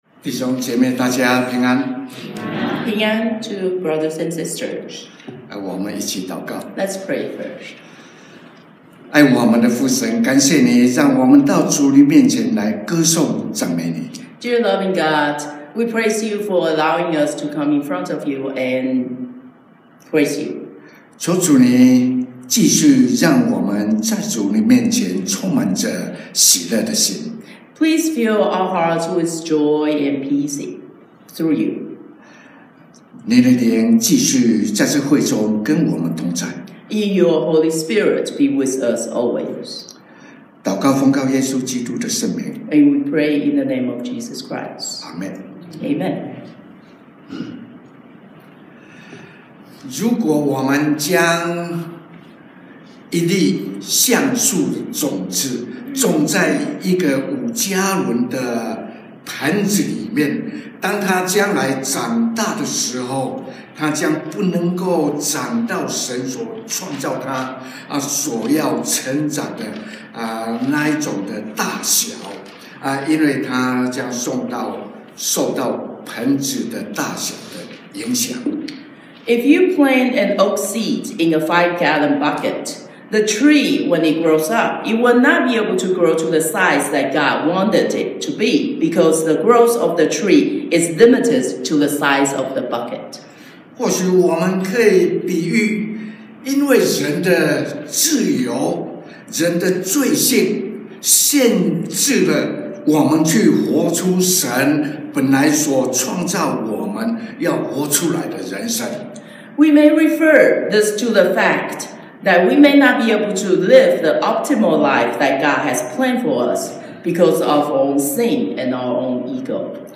講道題目 / Sermon Title